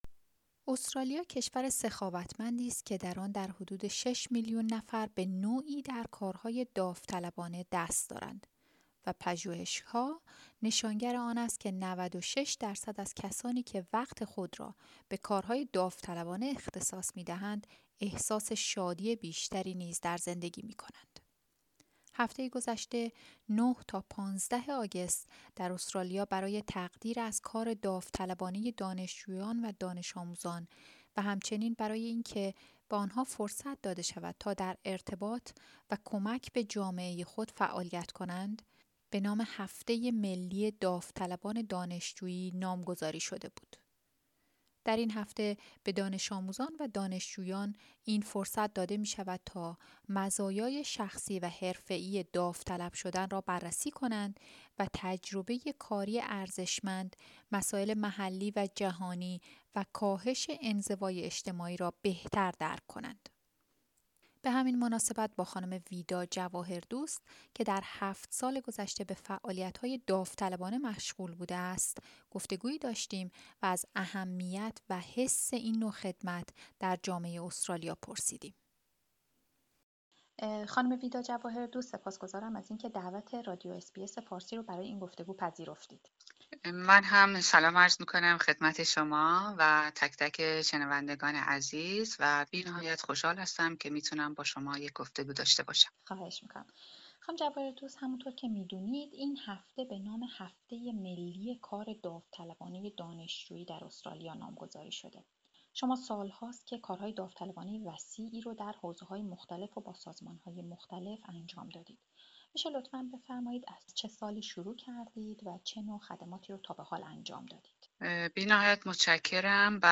گفتگو: هفته ملی کار داوطلبانه دانشجویان